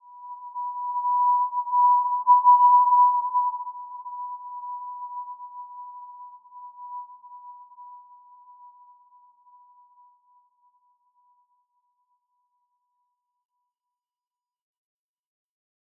Simple-Glow-B5-mf.wav